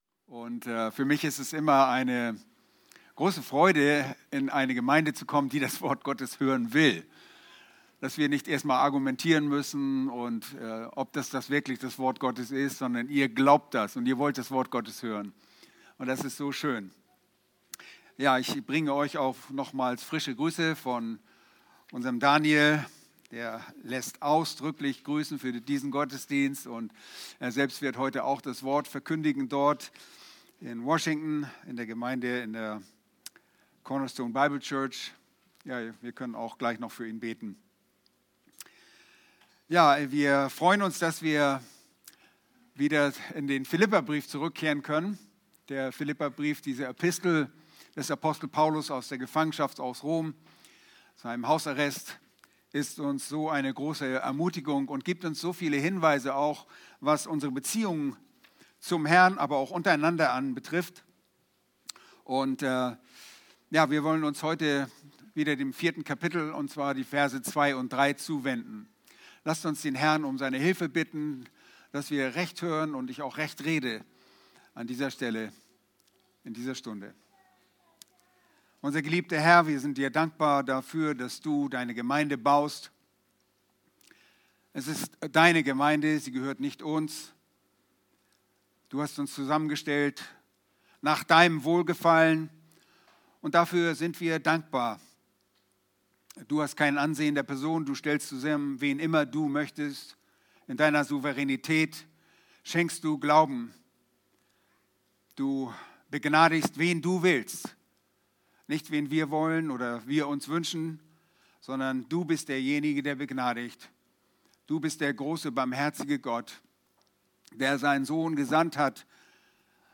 Predigten - Übersicht nach Serien - Bibelgemeinde Barnim